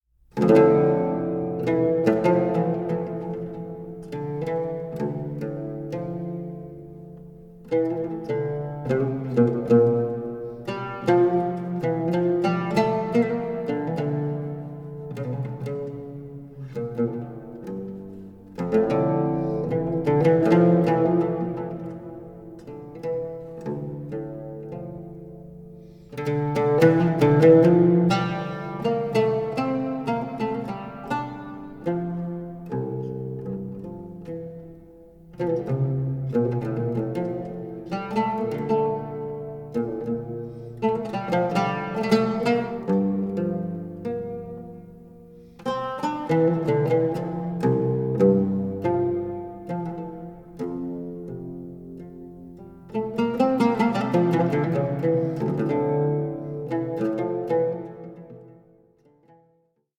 Sarabande (oud) 4:17